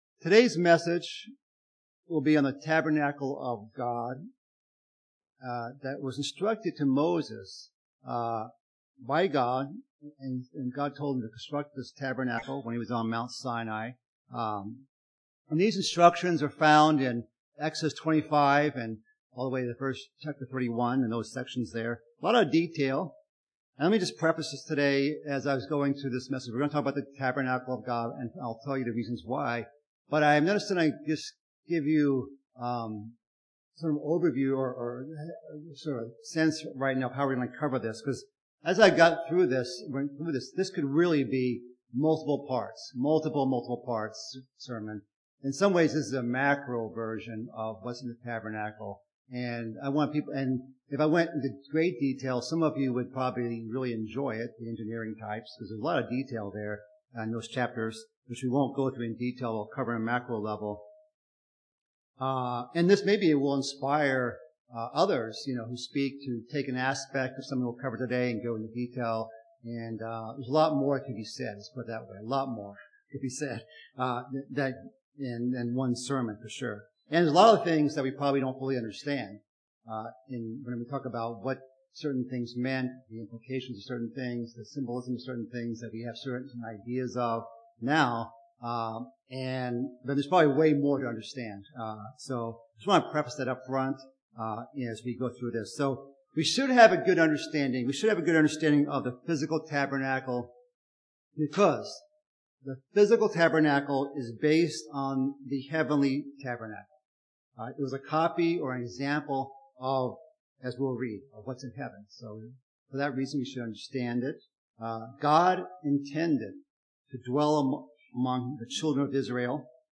Sermons
Given in Northwest Indiana